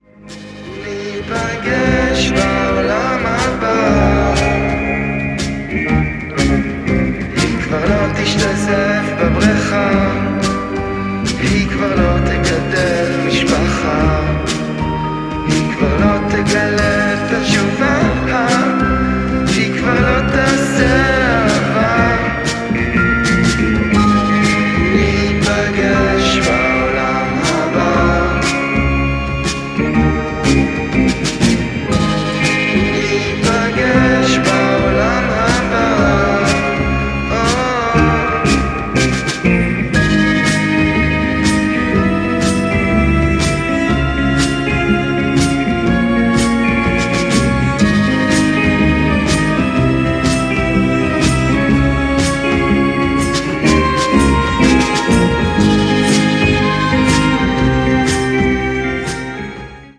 Drums
Vocals
Organs